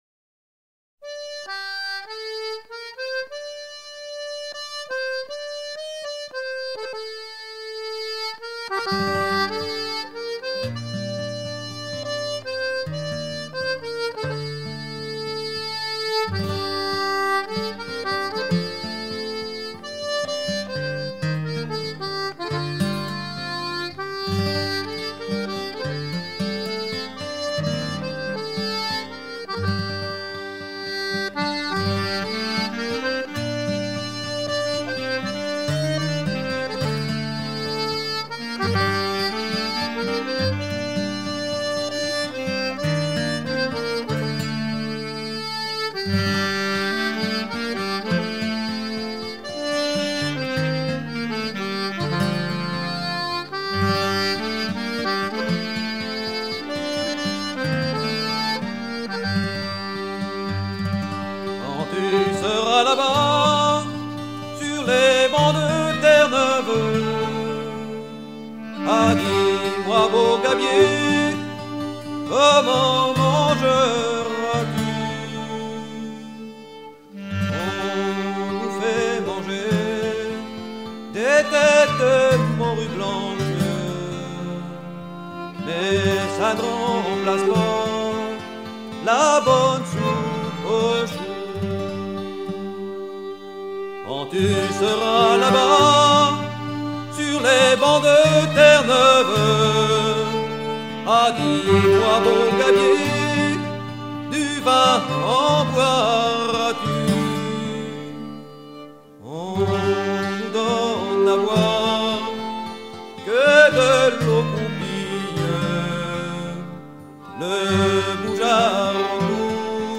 danse : ronde
Genre strophique
Pièce musicale éditée